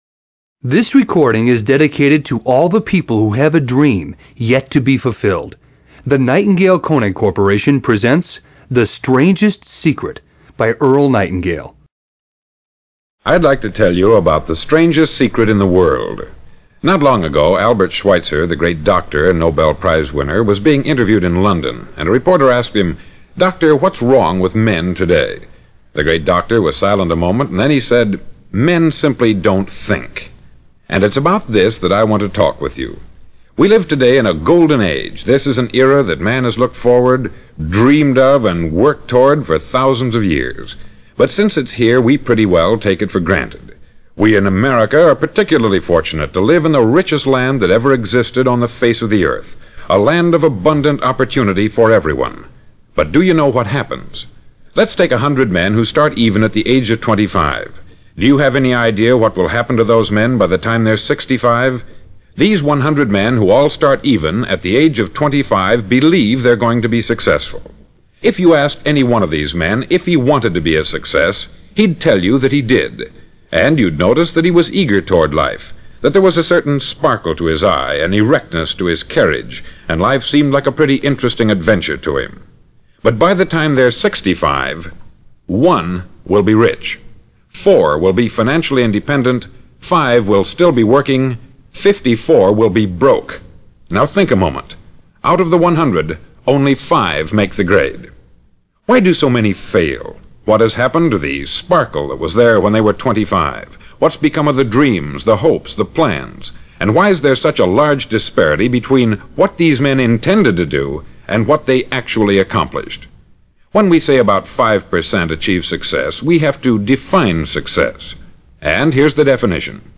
Grab a pen & paper and be ready to take some great notes, listen to this, the first personal development vinyl recorded ever recorded that outsold the music records in 1956 and still today is as accurate as every at what is in store for